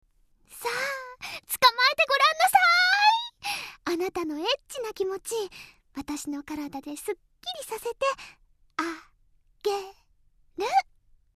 ドラマCD-first season-（仮）
水色・深澤葉月　CV　花澤香菜